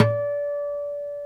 NYLON D 4 HM.wav